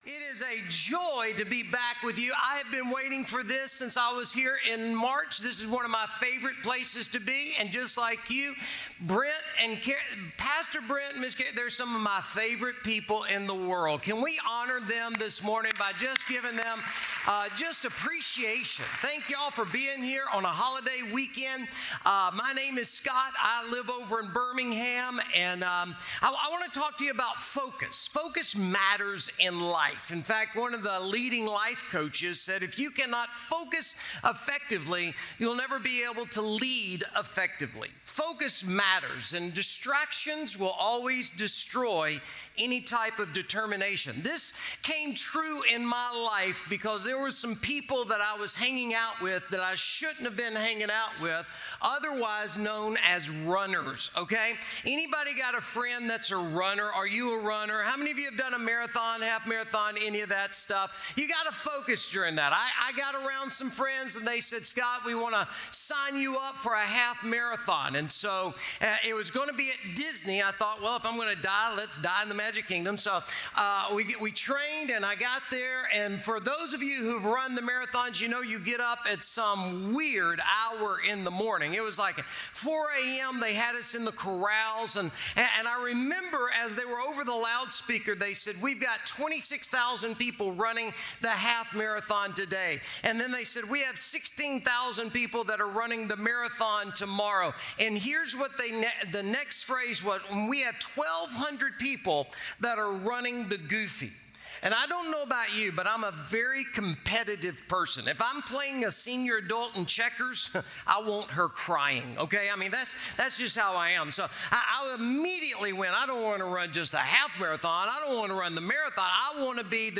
special message